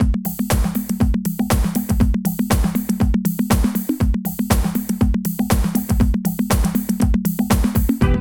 78 DRUM LP-R.wav